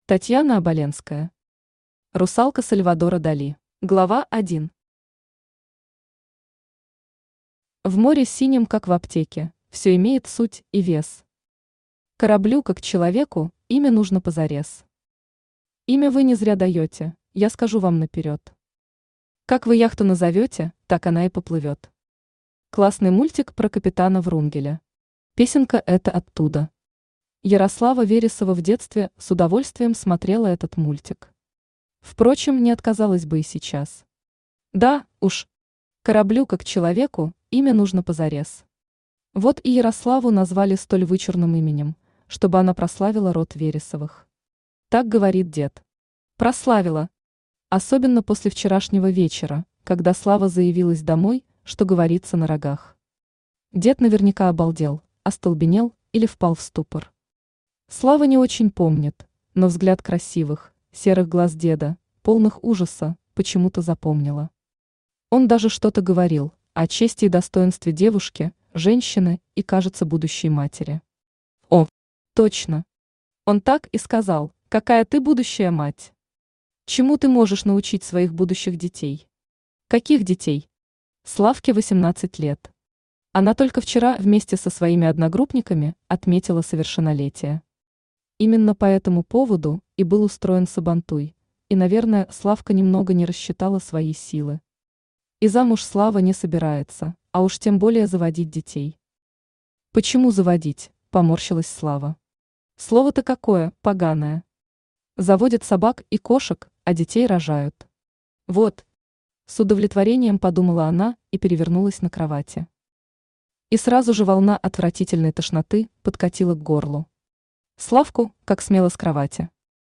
Аудиокнига Русалка Сальвадора Дали | Библиотека аудиокниг
Aудиокнига Русалка Сальвадора Дали Автор Татьяна Оболенская Читает аудиокнигу Авточтец ЛитРес.